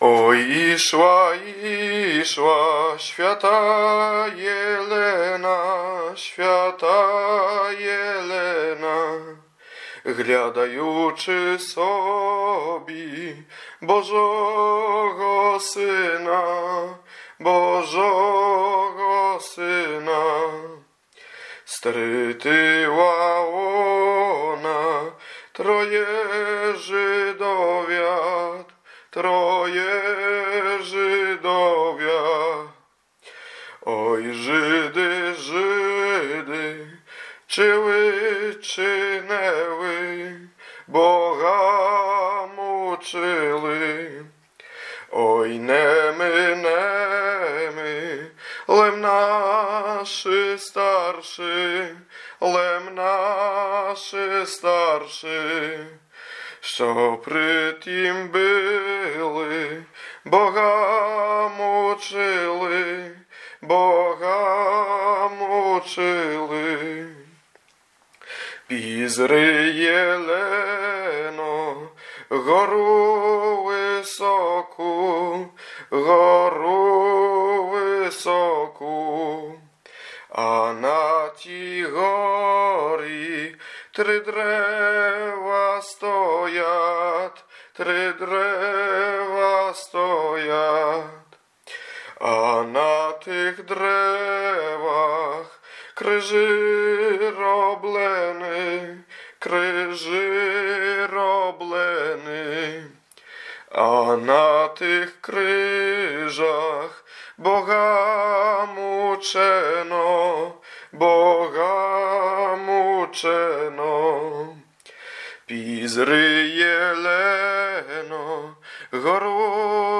Dolny Śląsk, powat legnicki, gmina Kunice, wieś Piotrówek
nabożne dziadowskie ballady o świętych